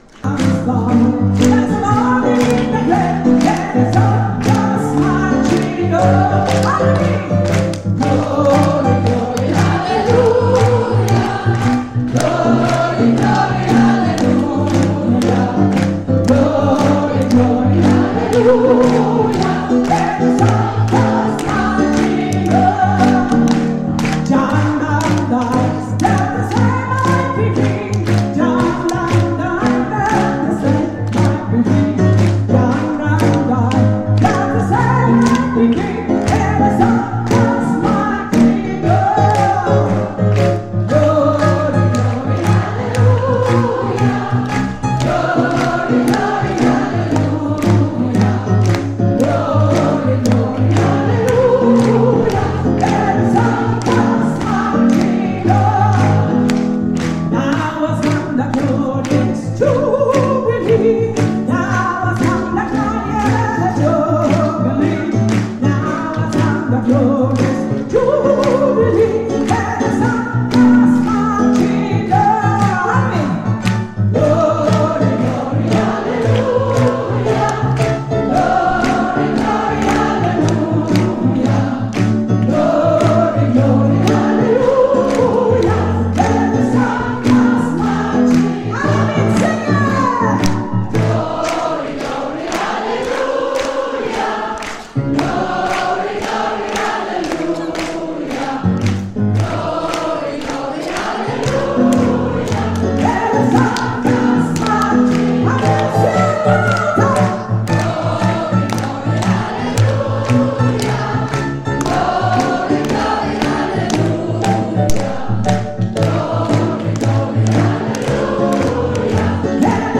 09 - Konzertaufnahmen - ChorArt zwanzigelf - Page 3
Wir sind laut, leise, kraftvoll, dynamisch, frisch, modern, bunt gemischt und alles, nur nicht langweilig!